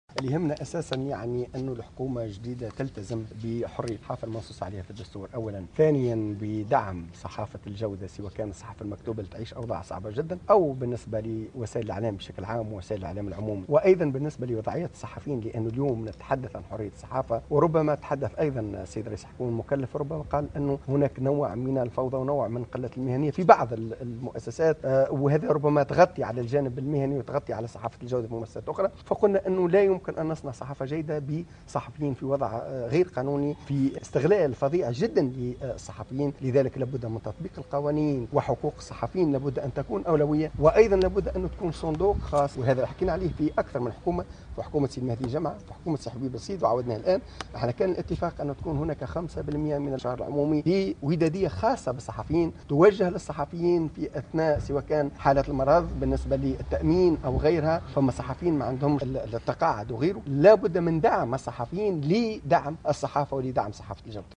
في تصريح إعلامي عقب اللقاء